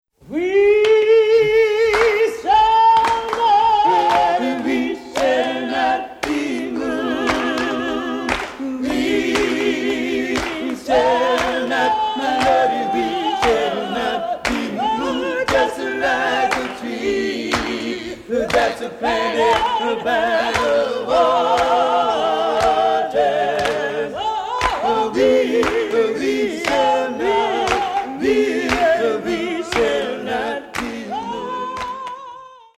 This song, a part of the Black sacred song repertoire, was one of several used throughout the union drives to organize Black laborers during the 1930's and the 1940's.